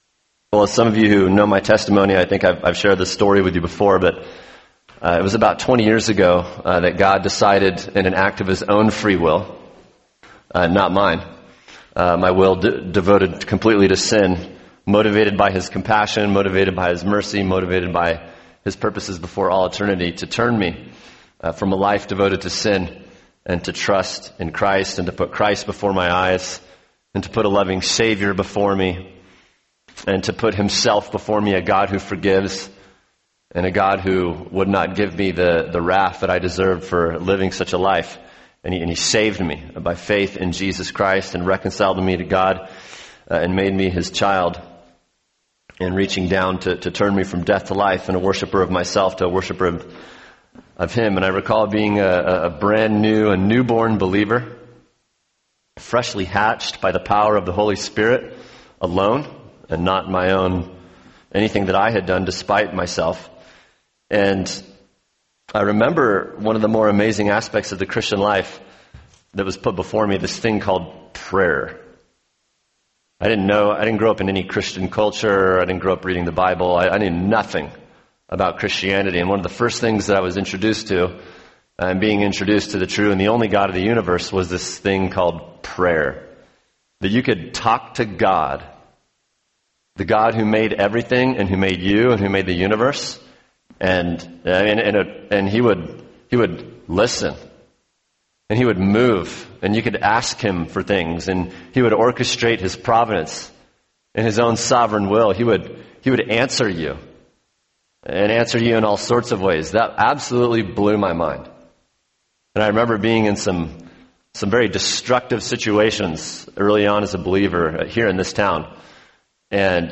[sermon] Luke 11:1-13 The Privilege of Prayer | Cornerstone Church - Jackson Hole